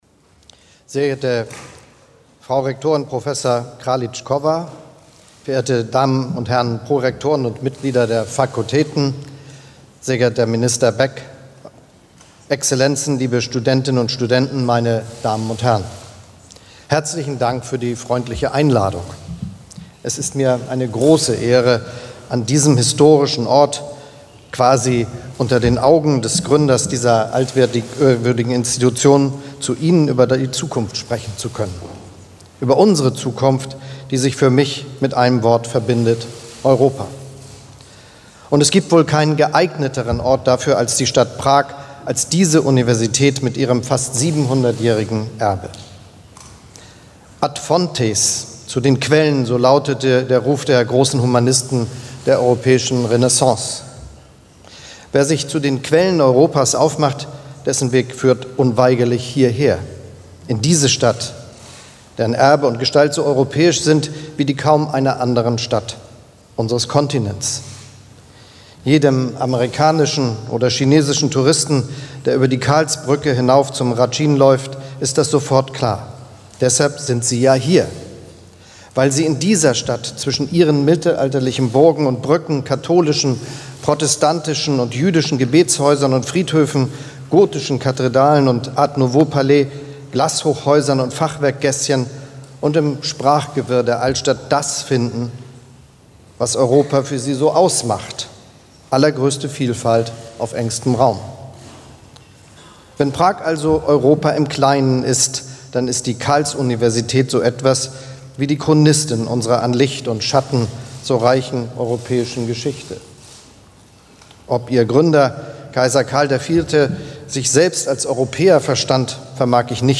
In einer Rede an der Prager Karls-Universität definiert Bundeskanzler Scholz die Folgen der Zeitenwende und beschreibt Anforderungen an das künftige Europa: eine geopolitische Europäische Union.
Video Rede von Bundeskanzler Olaf Scholz an der Karls-Universität